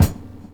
Tuned kick drum samples Free sound effects and audio clips
• Rich Highs Kick One Shot F# Key 49.wav
Royality free kick sample tuned to the F# note. Loudest frequency: 951Hz
rich-highs-kick-one-shot-f-sharp-key-49-A3q.wav